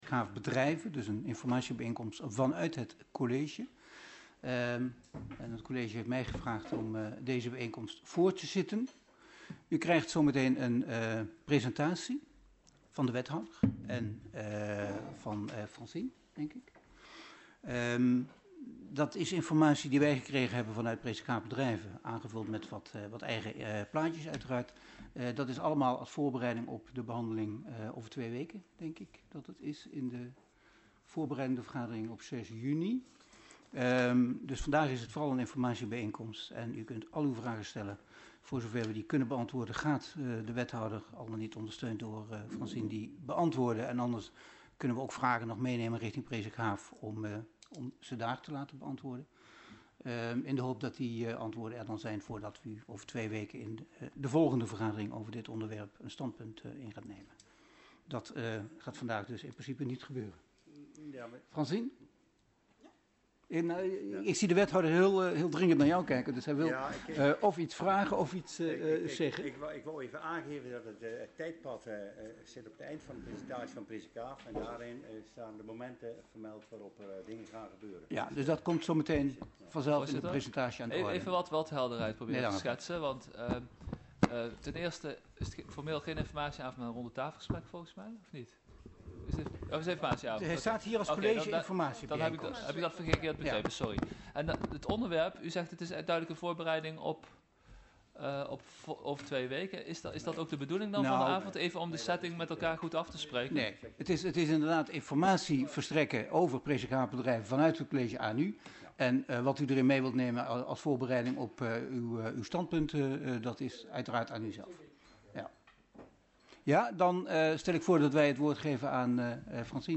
Locatie Hal, gemeentehuis Elst Toelichting College-Informatie-bijeenkomst over “Presikhaaf bedrijven” Agenda documenten 16-05-24 Opname Hal inzake College-Informatie-bijeenkomst over Presikhaaf bedrijven.MP3 27 MB